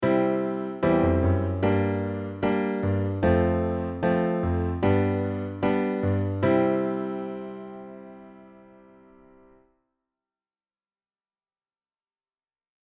さらっとやってきて、さりげなくお仕事をするのが特徴です。
他の人達よりも自然に世界の流れが変わってる感じがするよっ。